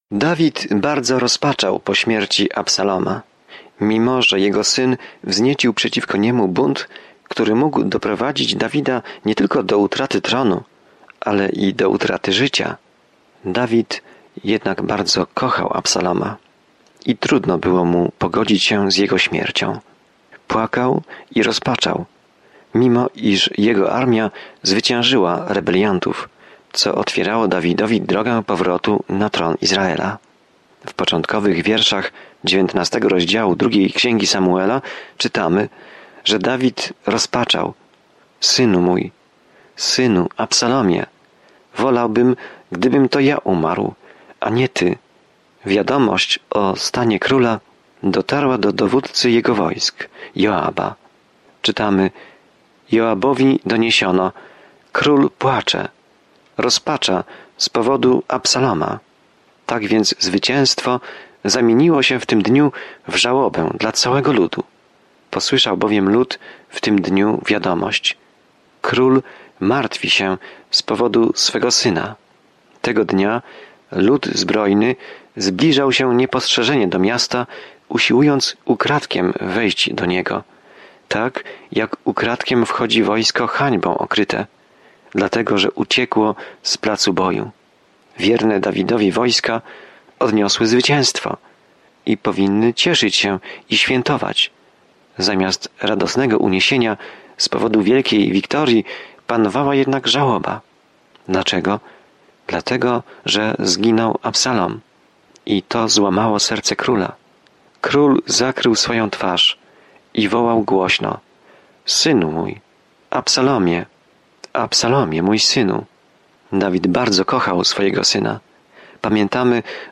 Codziennie podróżuj przez 2 Księgę Samuela, słuchając studium audio i czytając wybrane wersety ze słowa Bożego.